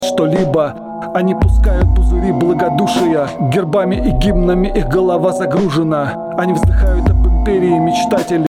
Кик "съедает" вокал
Выбрал понравившийся звук большого барабана. Удовольствие было недолгим - при ударе этого кика вокал словно проваливается - становится тише и теряет в насыщенности, "блекнет".
Пример поедания вокала киком(3).mp3